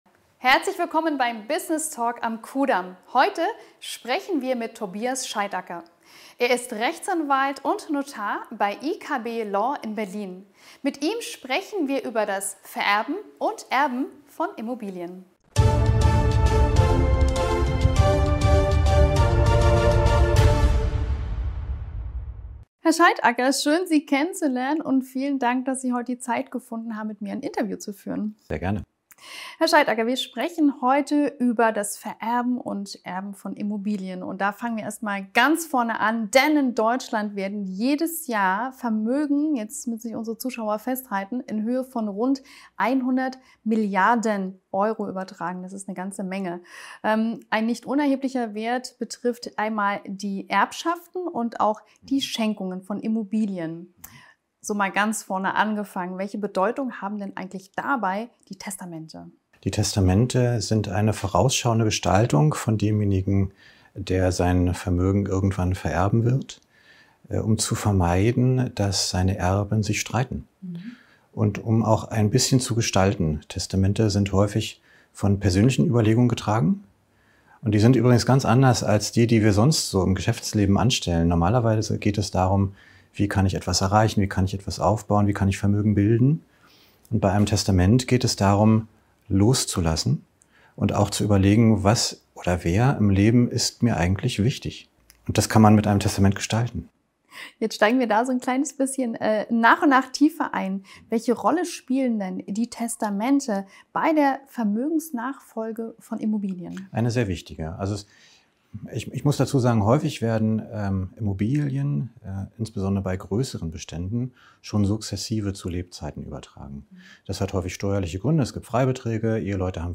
Herzlich Willkommen beim Businesstalk am Kudamm, heute zu Gast in den Räumlichkeiten der Kanzlei IKB Rechtsanwälte.